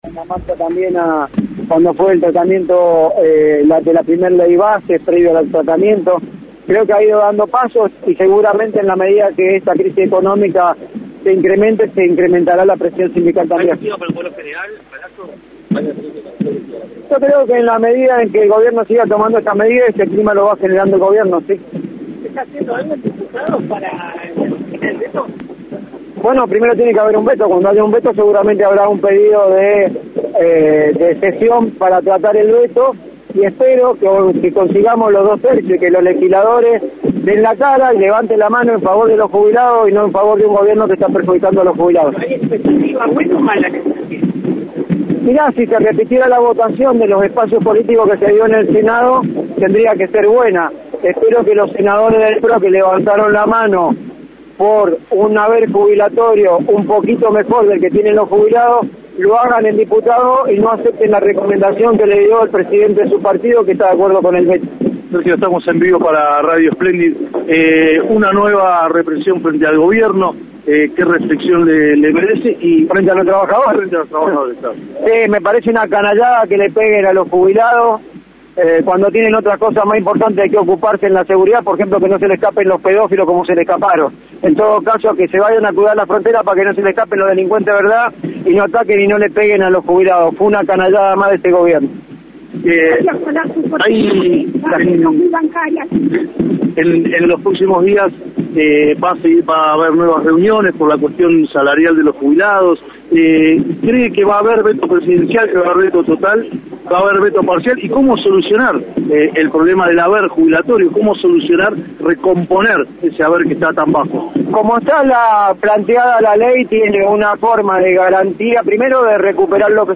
en “Así nos va” en Radio Splendid (AM 990).